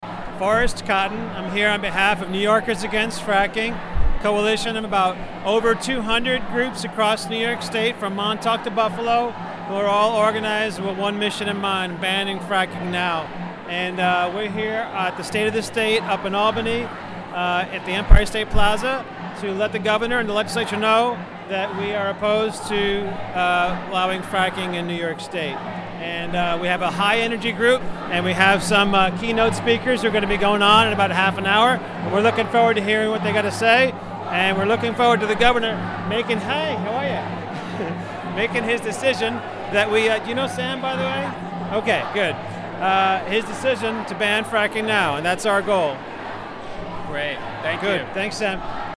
Wave Farm | Former Greene County Legislator Forest Cotten at the Anti-Fracking Rally prior to Gov. Andrew M. Cuomo's State of the State Address.